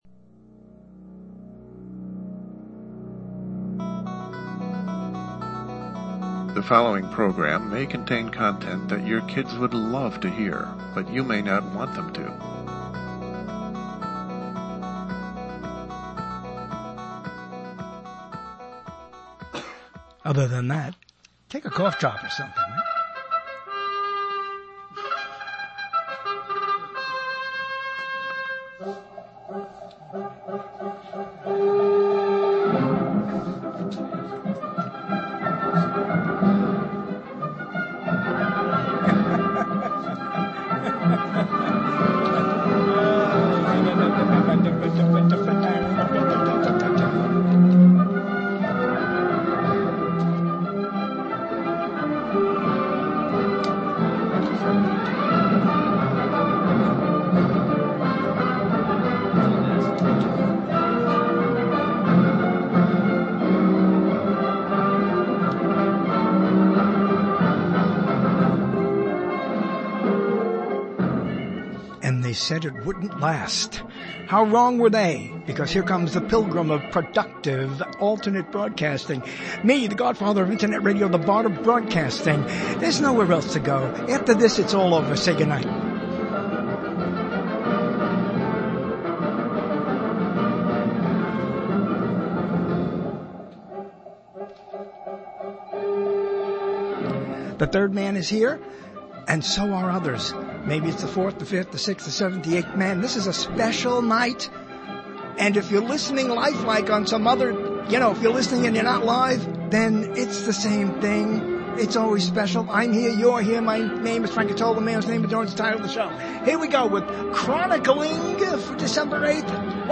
It’s our host’s birthday, a rare time it falls on a show date. Guests are live in the studio, via Skype and surprise.